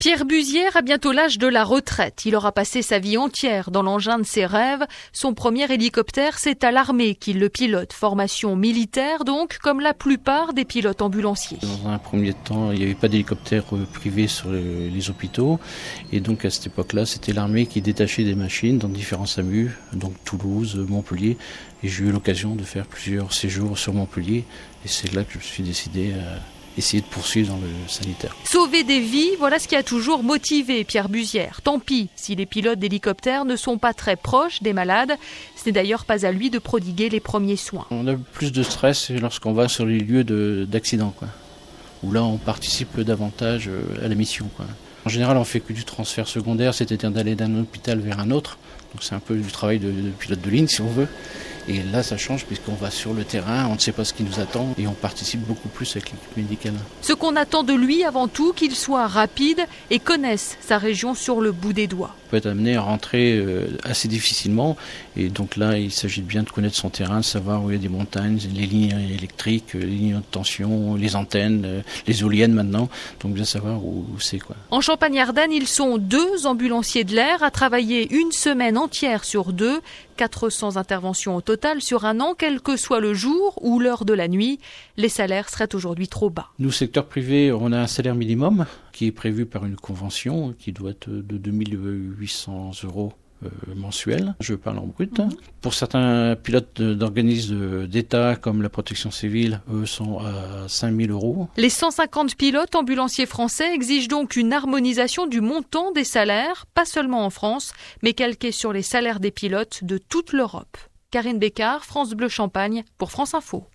Métier passion Portrait
pilotes-d-helicoptere-1.mp3